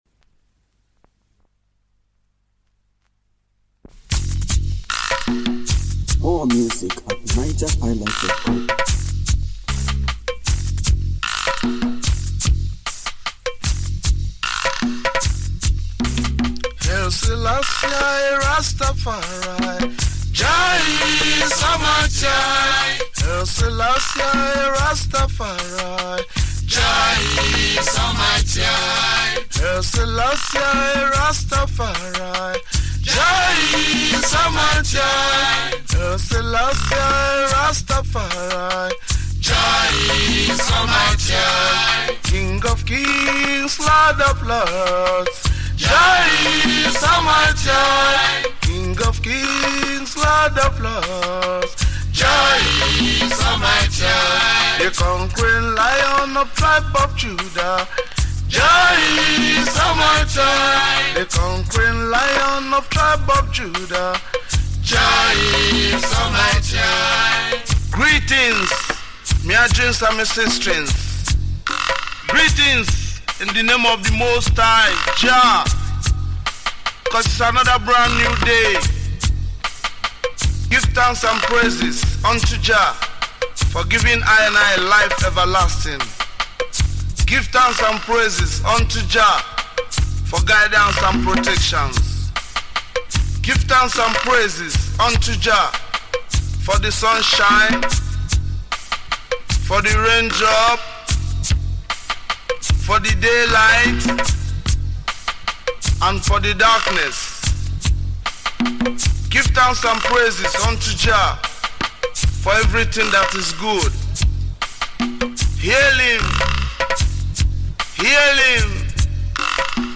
Home » Ragae